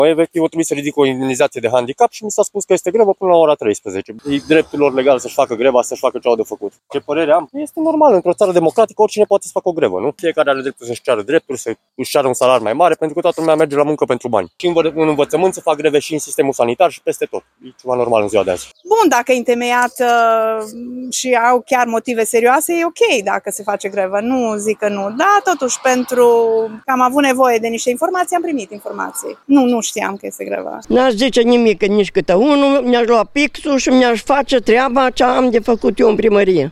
voxuri-proteste-Arad.mp3